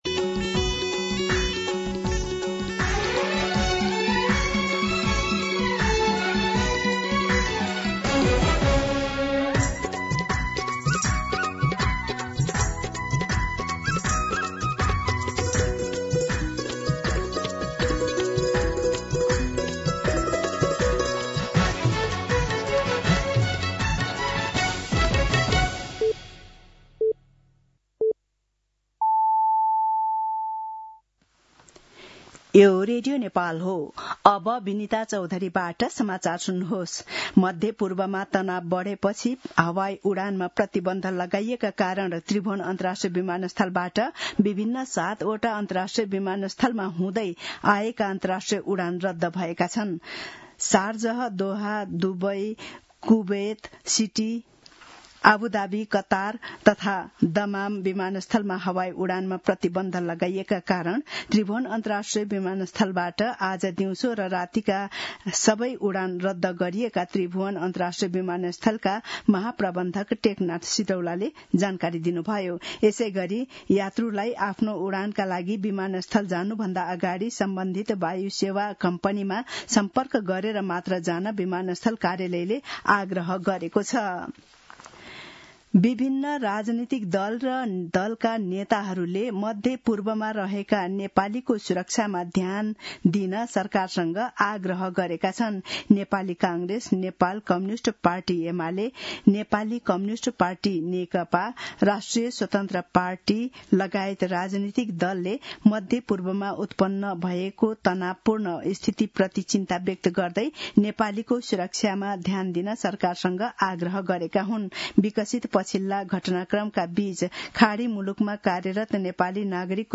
मध्यान्ह १२ बजेको नेपाली समाचार : १७ फागुन , २०८२
12pm-News-17.mp3